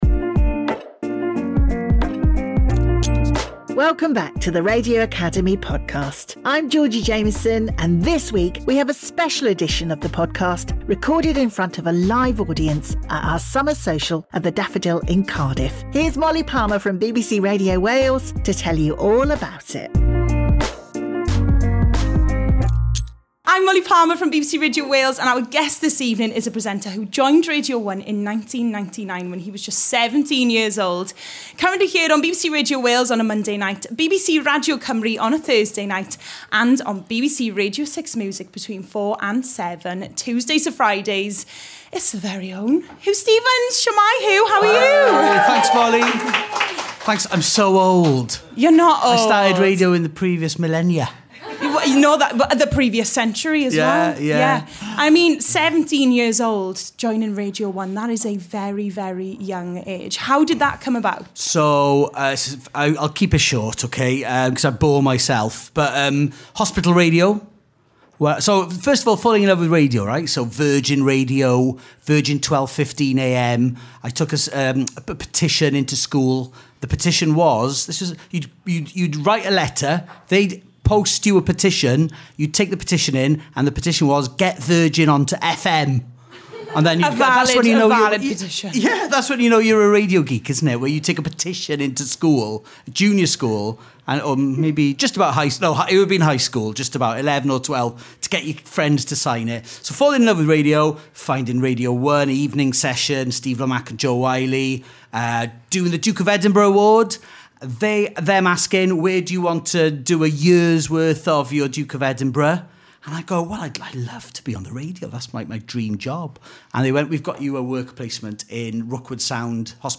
Recorded at the Wales branch Summer Social in Cardiff
In front of a live audience, the pair discuss Huw's journey into radio, the launch of his new show on BBC 6 Music, and his newly published book 'Wales - 100 Records'.